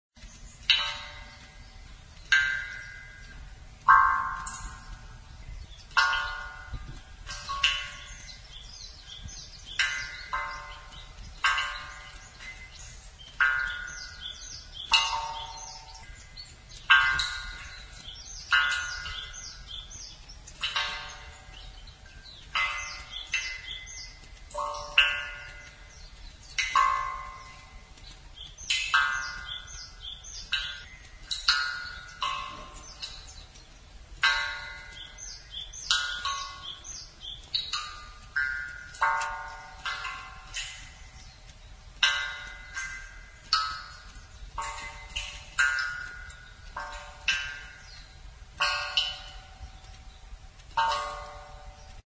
Suikinkutsu
(Koto of water-drips)
Water drips from the hole onto the water in the pan and makes a low sound. The big jar echoes with the sound of water-drips.
You can hear the beautiful sound like a Koto, a Japanese harp.
Suikinkutsu.mp3